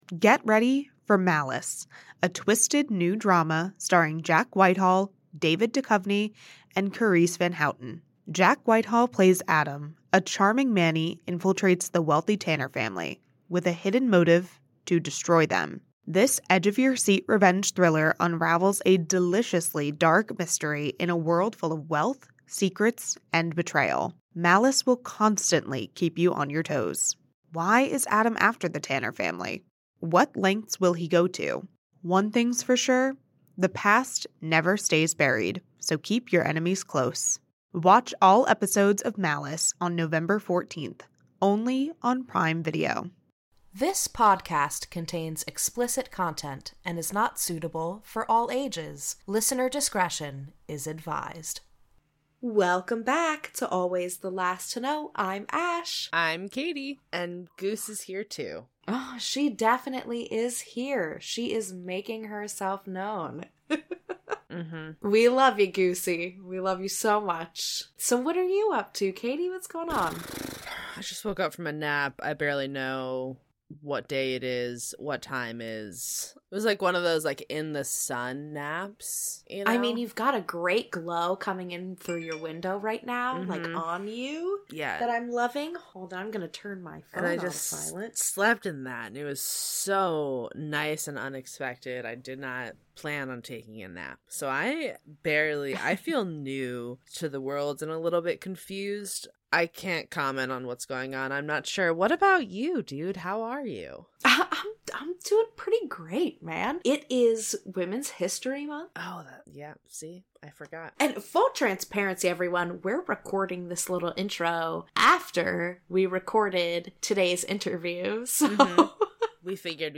A Conversation with Congresswoman Bonnie Watson Coleman
We hope you enjoy this insightful and passionate conversation.